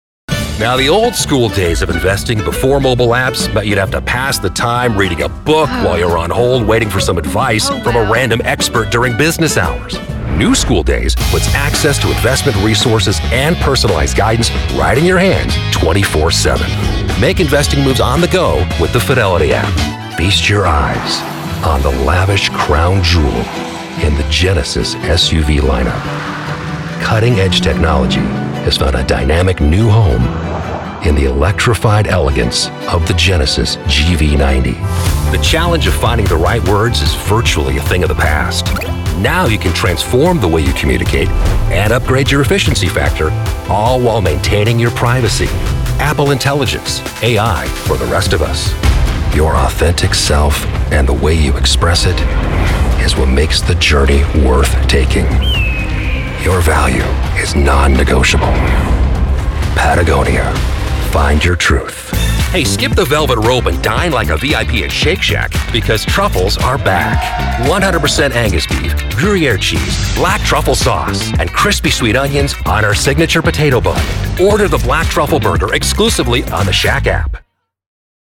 Adult (30-50) | Older Sound (50+)
Our voice over talent record in their professional studios, so you save money!
0512Demo_-_Commercial.mp3